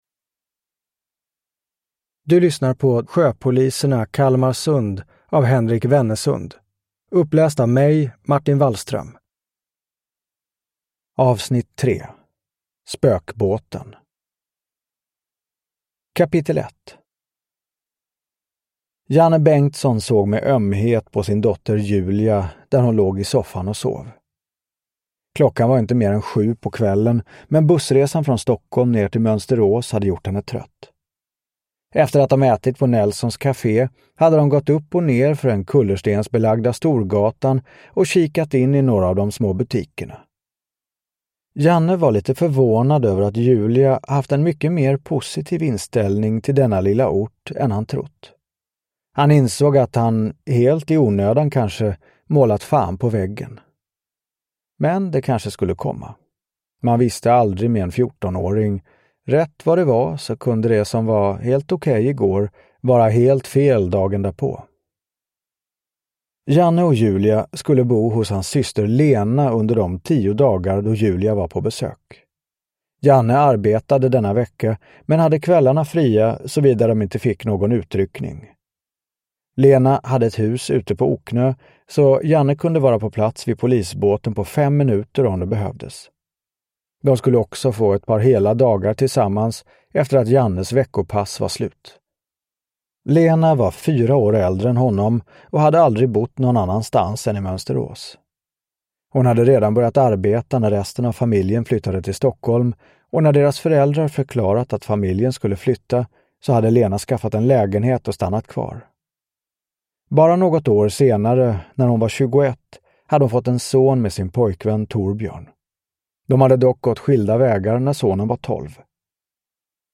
Spökbåten (ljudbok) av Henrik Wennesund